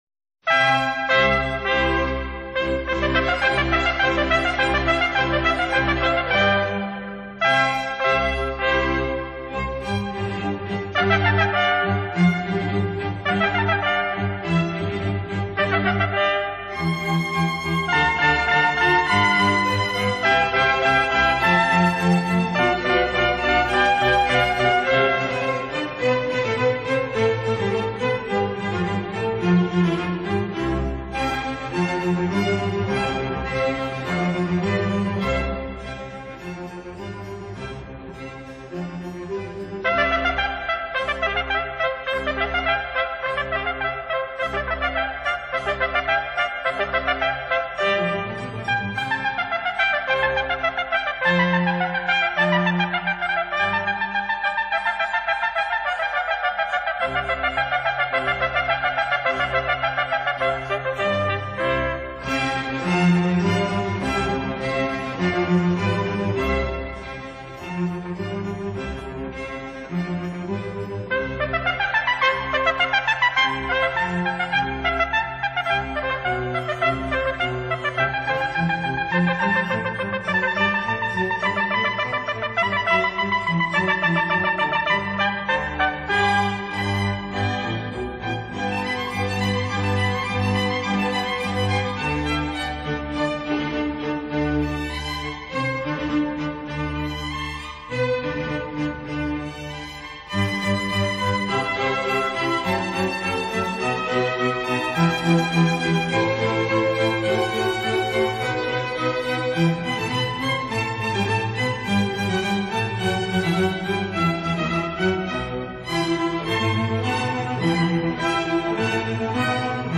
小號協奏曲輯